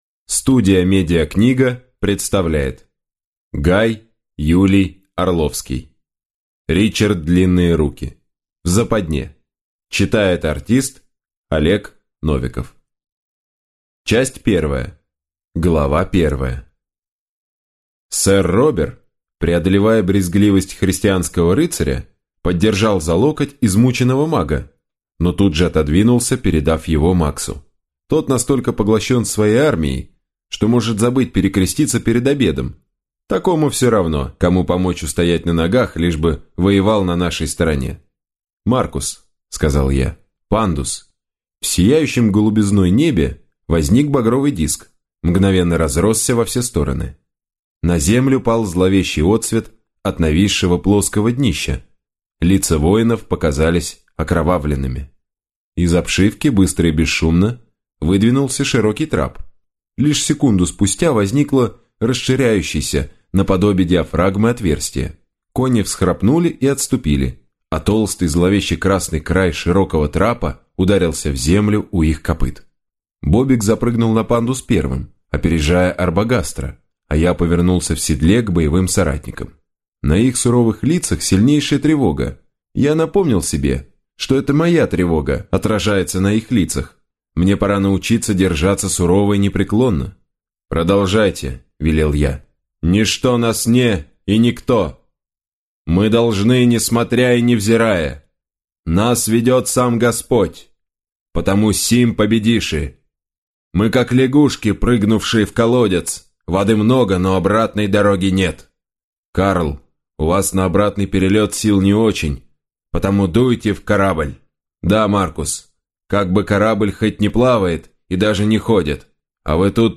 Аудиокнига Ричард Длинные Руки. В западне | Библиотека аудиокниг